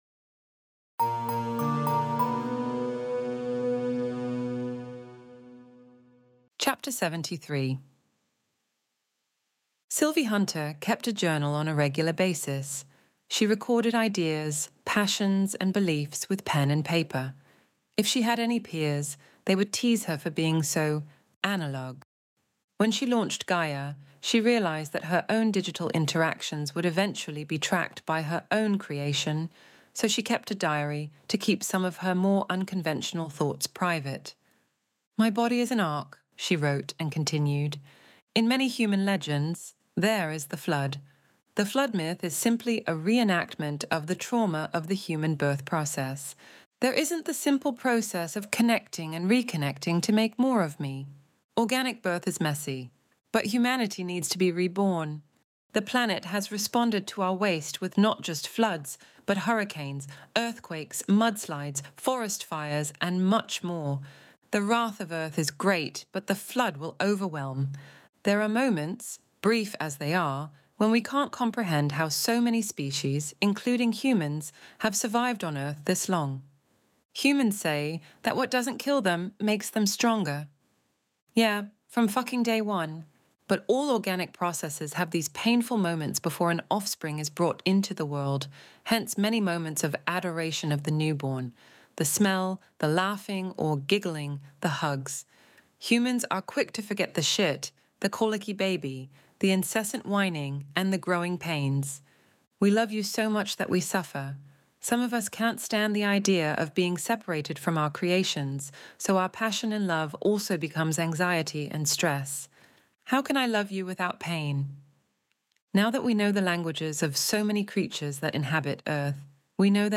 Extinction Event Audiobook Chapter 73